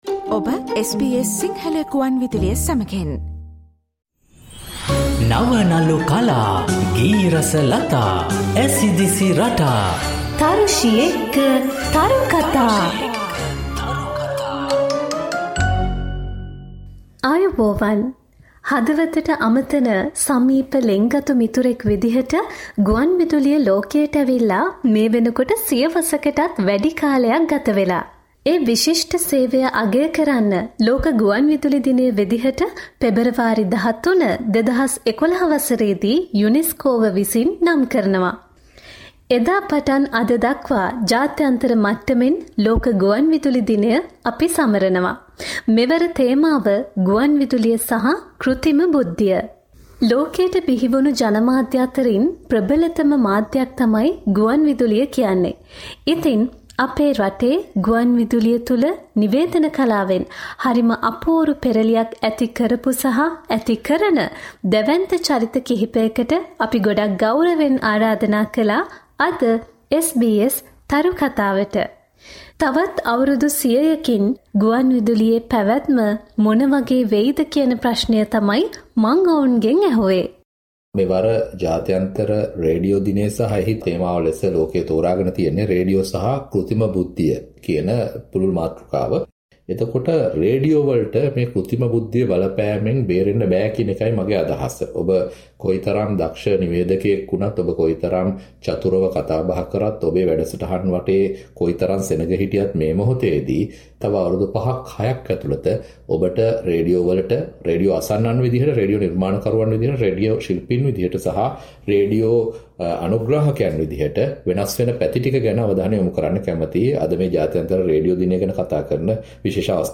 To mark World Radio Day, we spoke with veteran and pioneering radio journalists about how they see the future of radio.